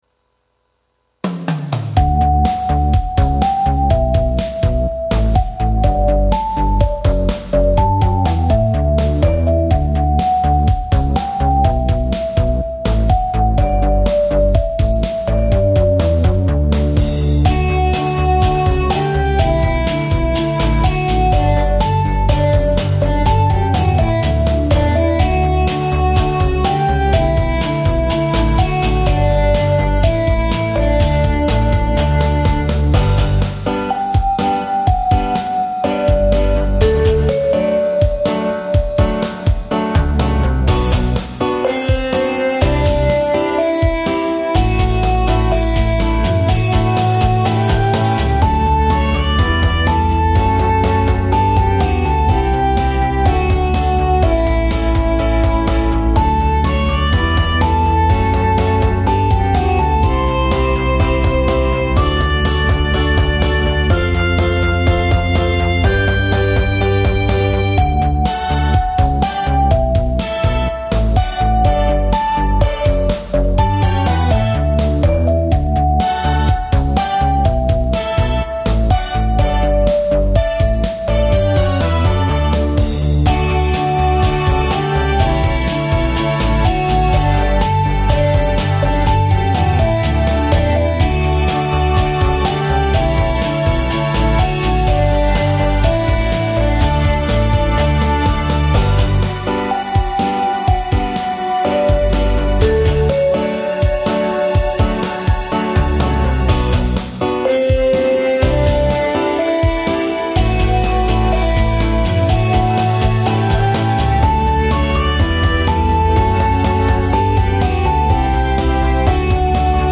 SD-50を使った音楽製作の練習。
リズム隊にリズムを持たせることを意識して作ったはず。
二週目以降は、ブラスやストリングスを加えて演奏させている。